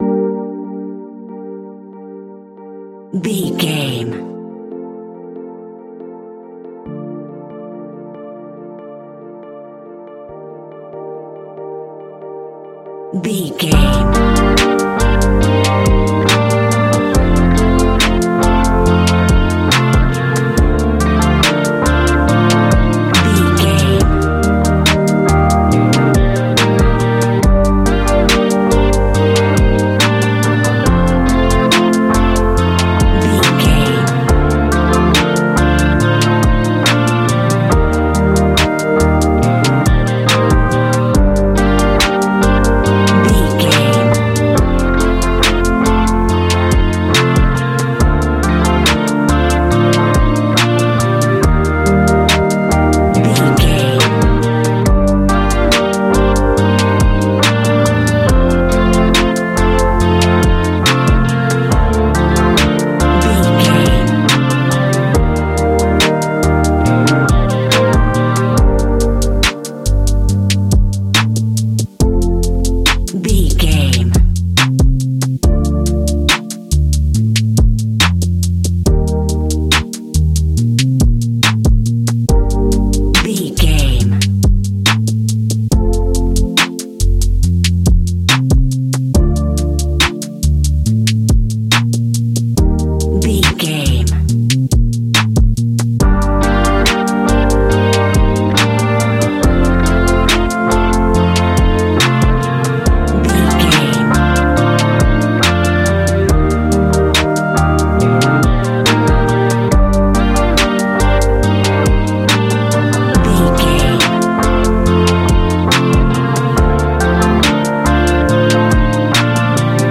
Ionian/Major
A♯
chilled
laid back
Lounge
sparse
new age
chilled electronica
ambient
atmospheric
morphing
instrumentals